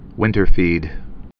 (wĭntər-fēd)